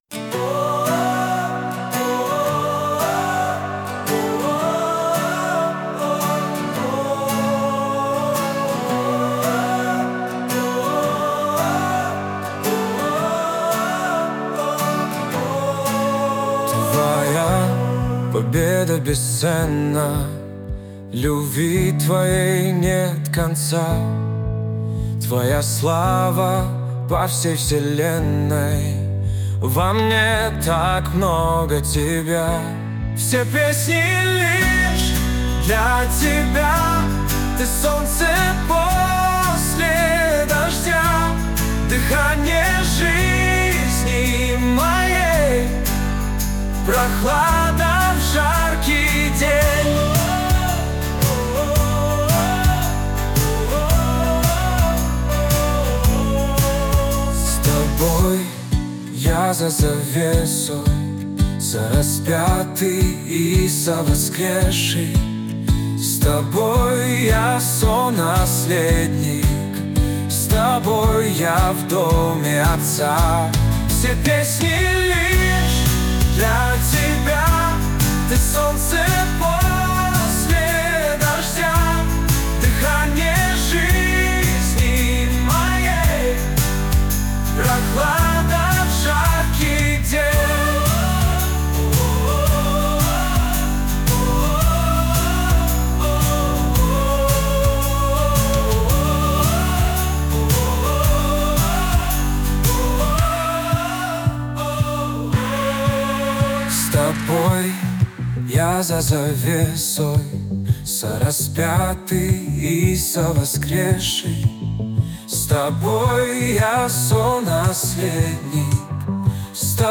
песня ai
193 просмотра 177 прослушиваний 22 скачивания BPM: 73